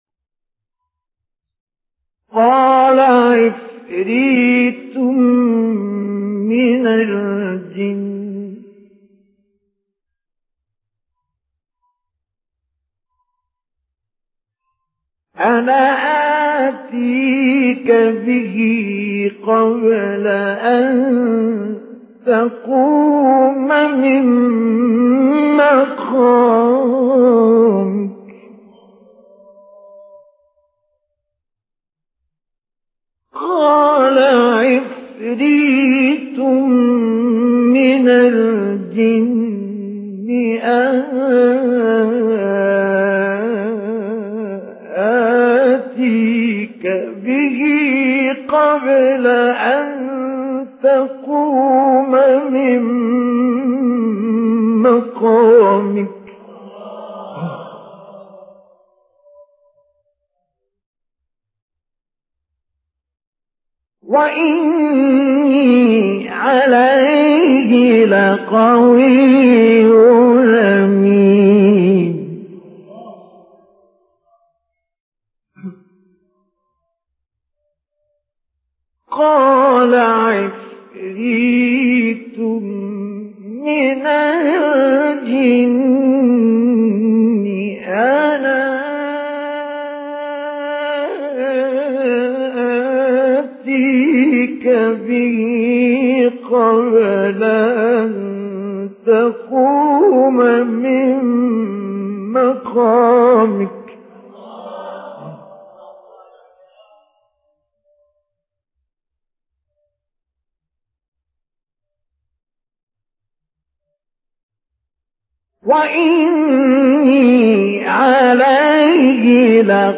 به گزارش ایکنا، چهار اجرای متفاوت از تلاوت آیه 39 سوره مبارکه نمل با صوت مصطفی اسماعیل، قاری قرآن کریم از کشور مصری در کانال تلگرامی اکبرالقراء منتشر شده است.
تلاوت آیه 39 سوره نمل در سال 1974 میلادی